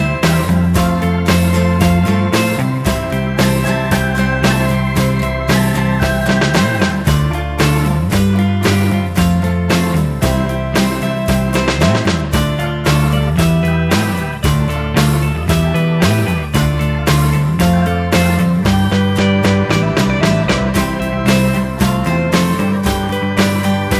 No Harmony Pop (1960s) 2:49 Buy £1.50